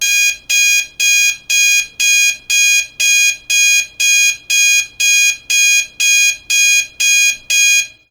alarmClock.ogg